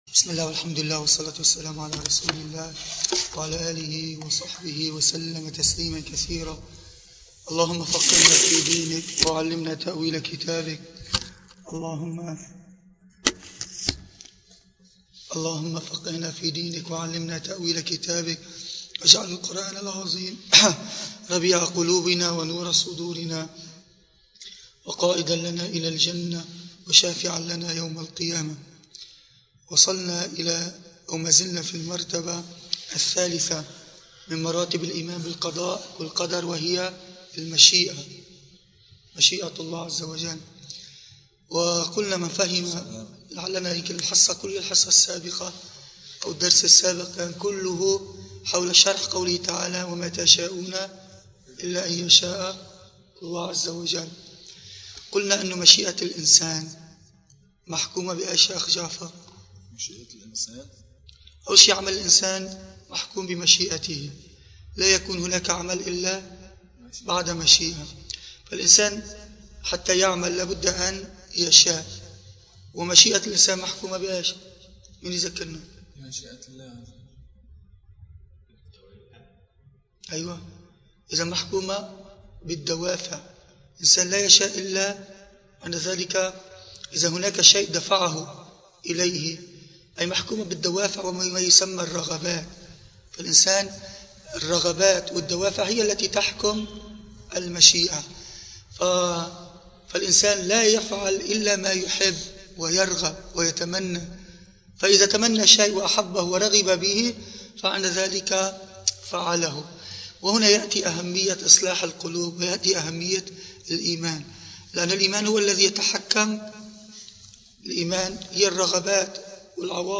المكان: مسجد القلمون الغربي